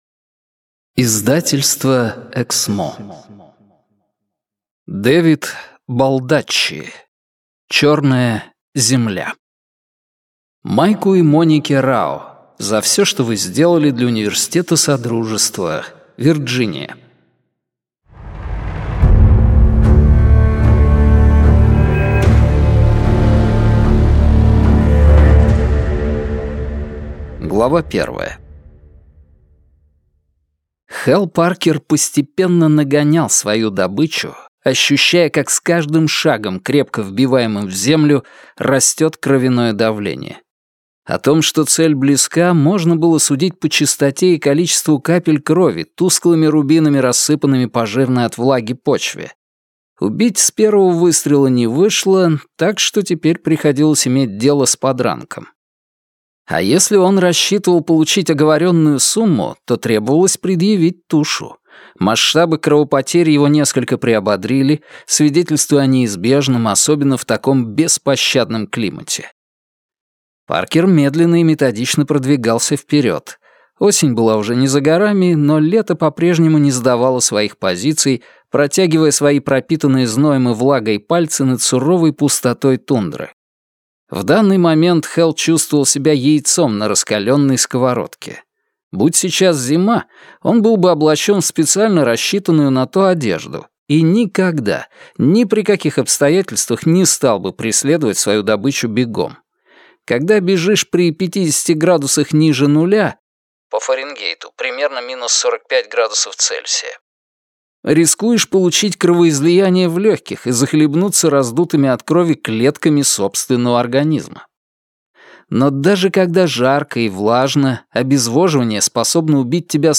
Аудиокнига Черная земля | Библиотека аудиокниг